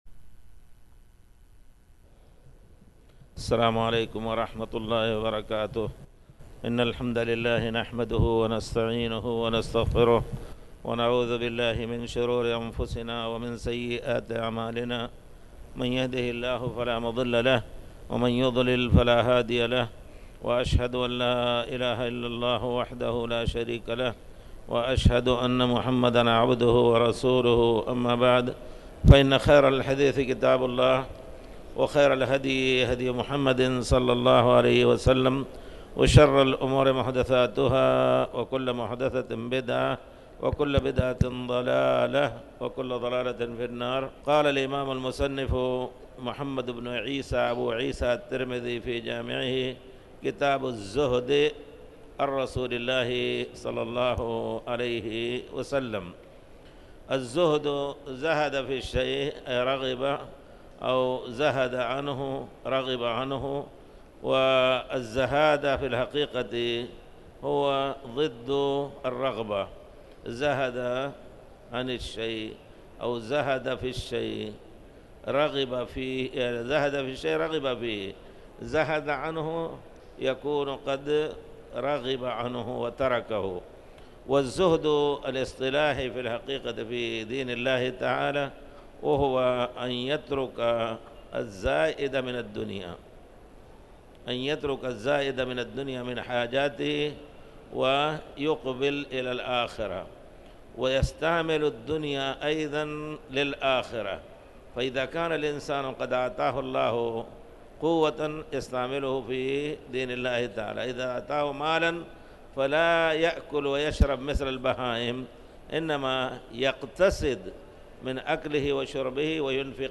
تاريخ النشر ٣ جمادى الأولى ١٤٣٩ هـ المكان: المسجد الحرام الشيخ